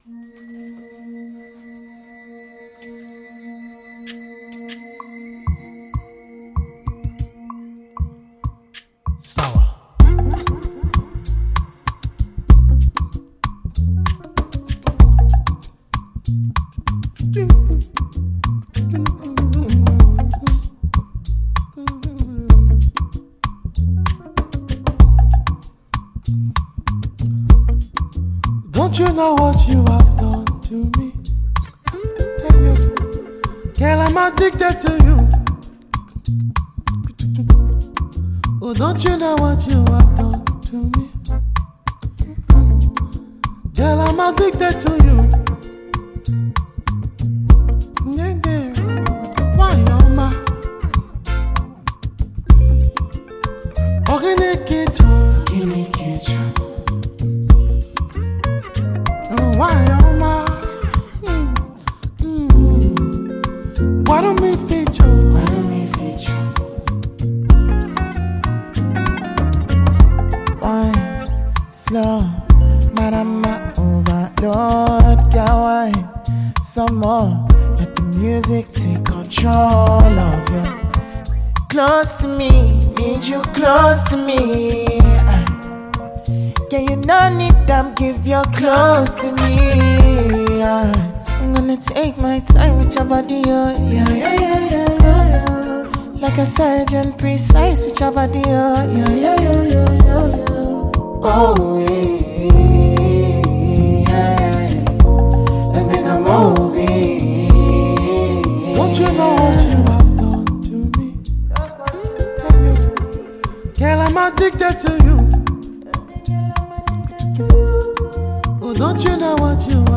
” another catchy-sounding tune.
Afro highlife duo